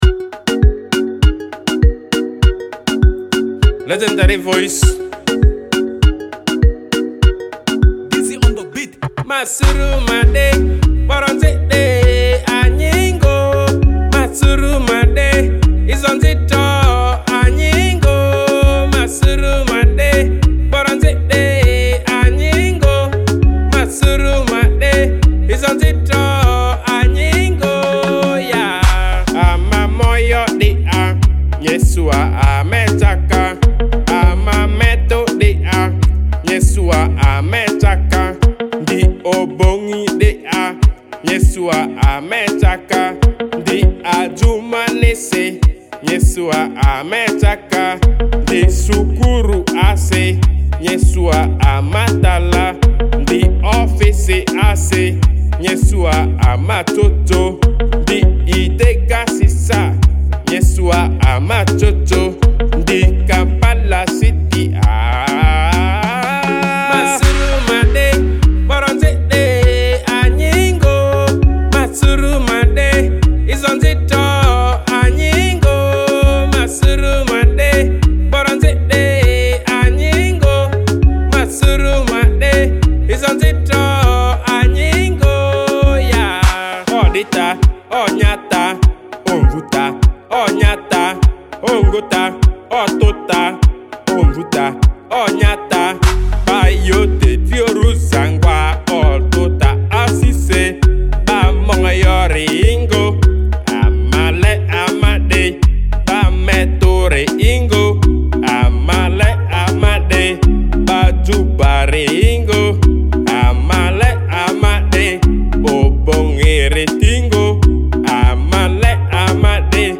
Genre: Afro